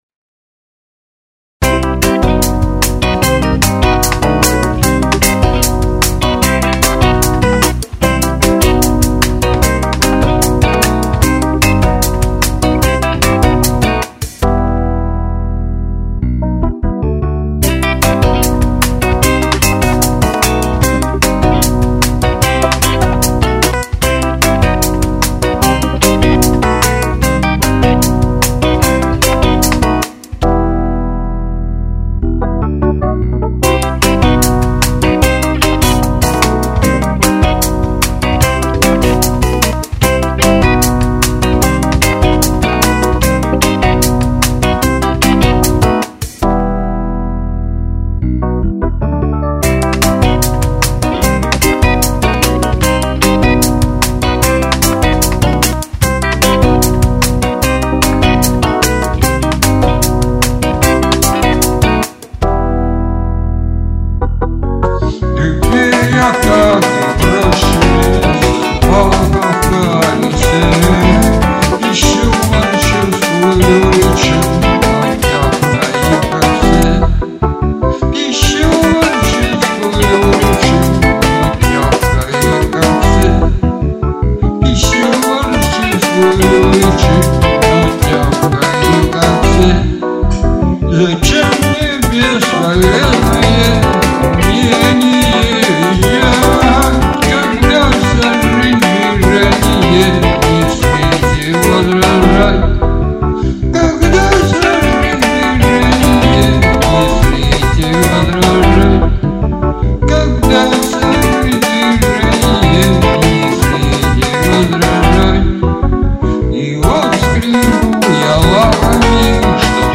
Фанк (337)